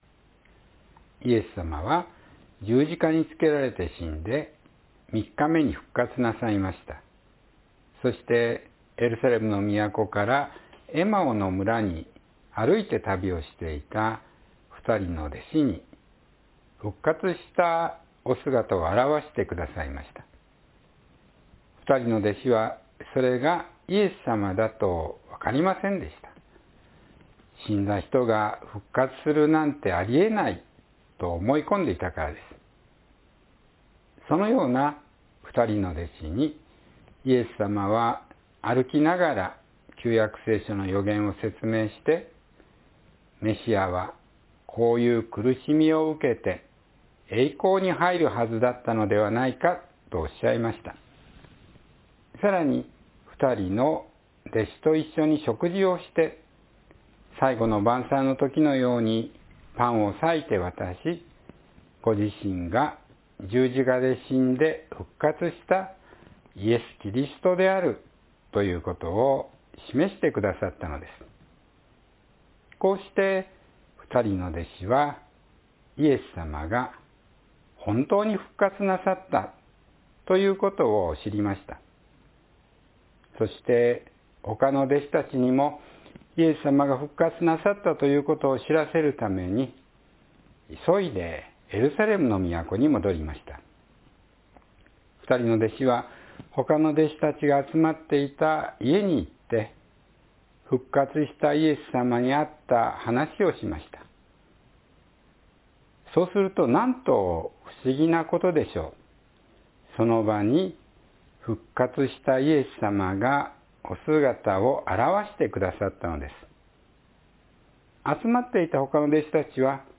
イエスさまの証人となる（2026年4月19日・子ども説教）